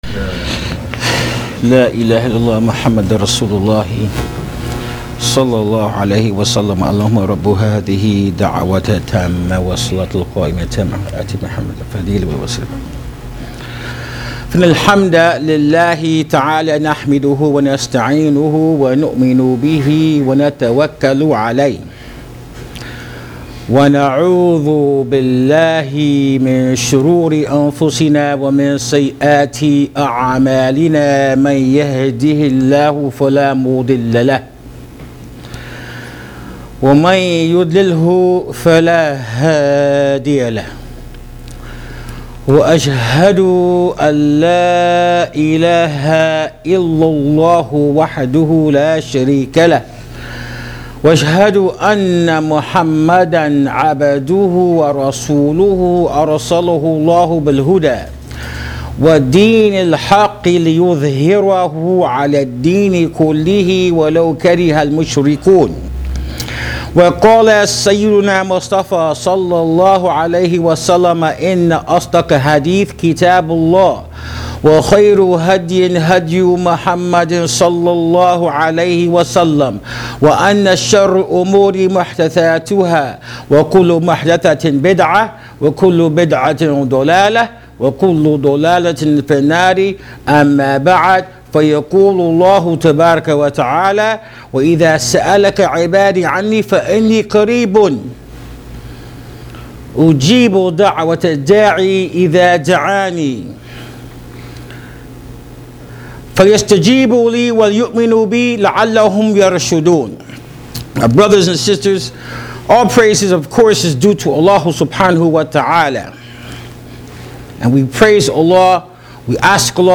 Free Audio Khutba
In the sunna of the Prophet (SAWS), there are many ways of calling upon Allah sub’haanahu wa ta’ala, and in this khutbatul Jum’ah taped @ Masjid Ibrahim Islamic Center in Sacramento, we explain in sha Allah a few of the oft repeated du’aa of the Prophet (SAWS) their profound meanings, and what they mean for you as a Muslim.